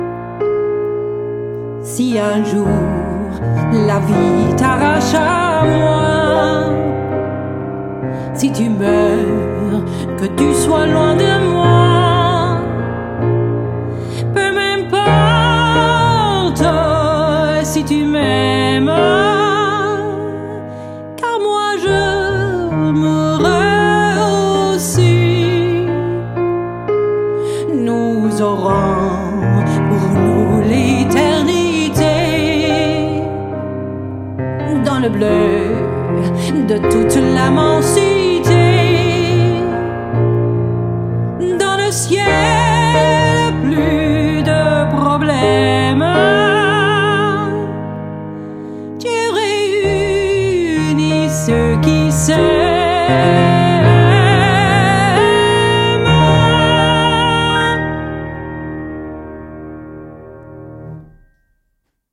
Gesang
Klavier